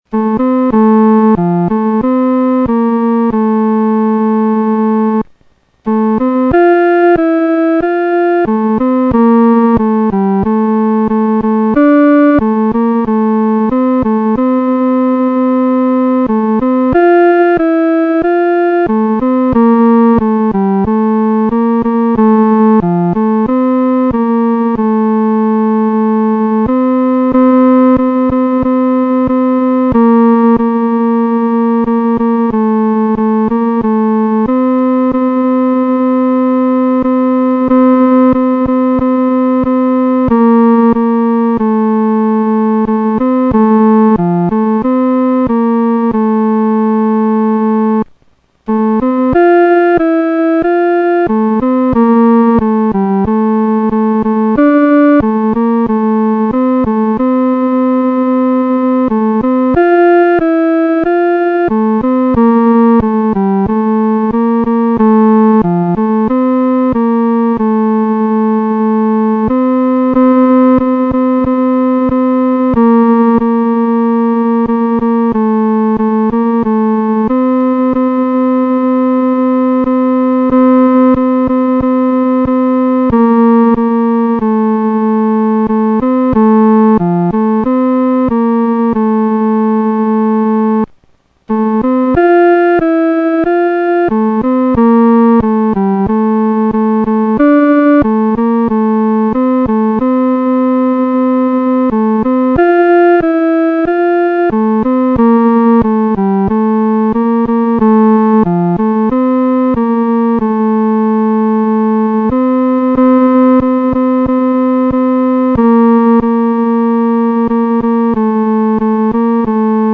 独奏（第三声）